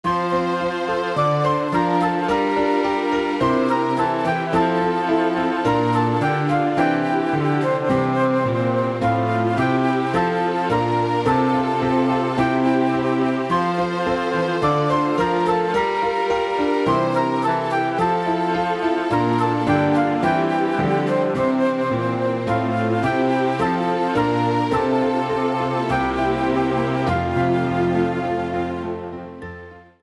Rubrika: Vánoční písně, koledy
Karaoke